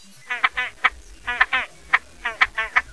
Skäggdopping (Podiceps cristatus).
skaggdopping.wav